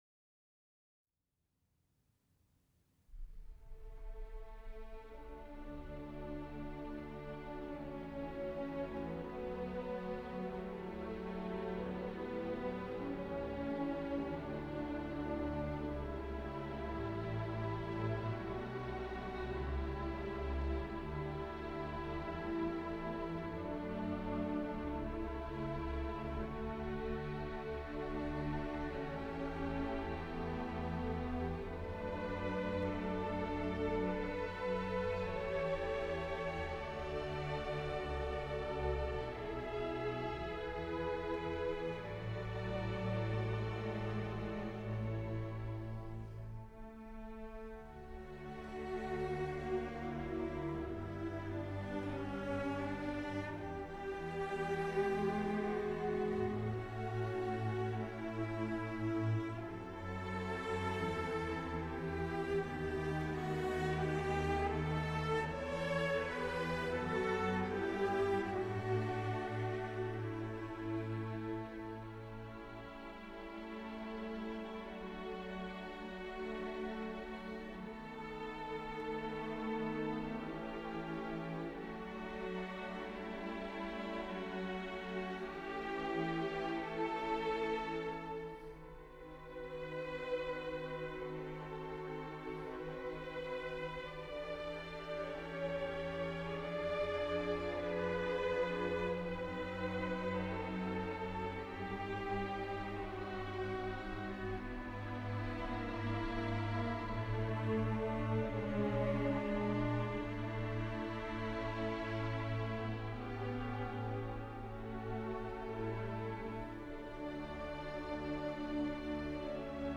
Mahler_-Symphony-3_-In_D_Minor_VI_Langsam_Ruhevoll_Empfunden.mp3